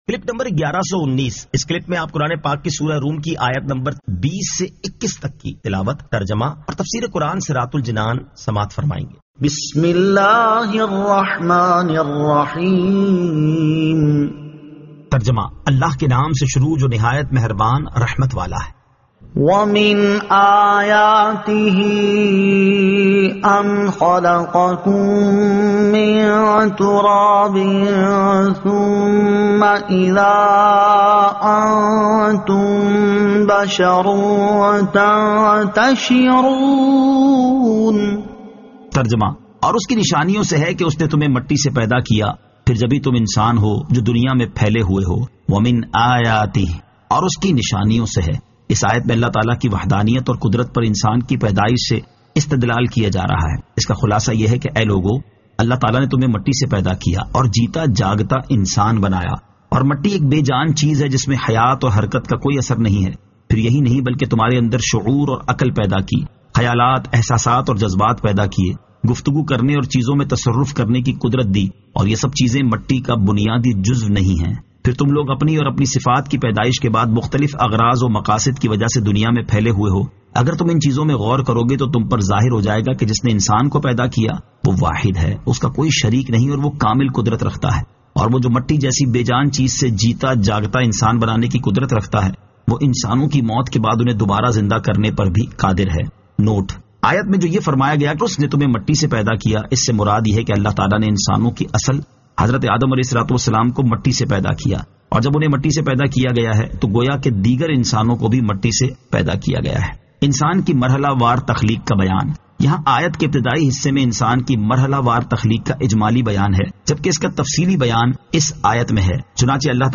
Surah Ar-Rum 20 To 21 Tilawat , Tarjama , Tafseer